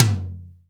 RS TOM 1-2.wav